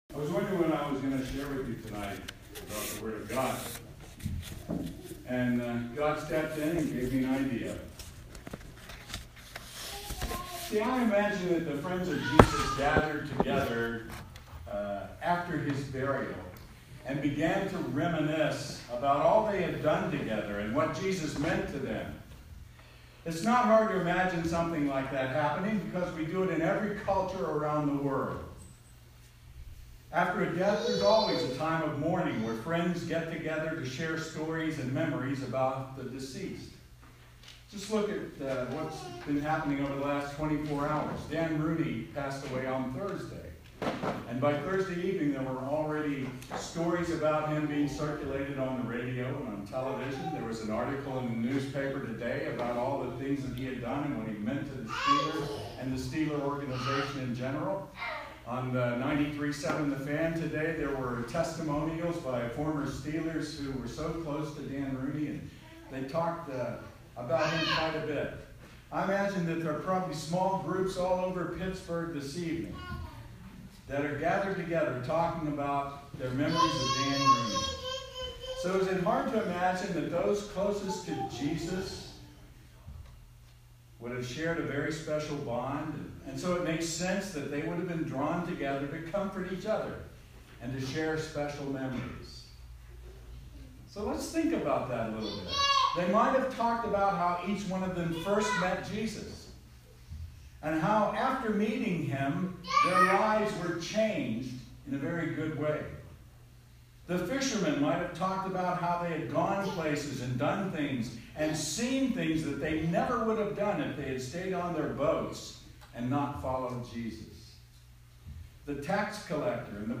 Sermon – Good Friday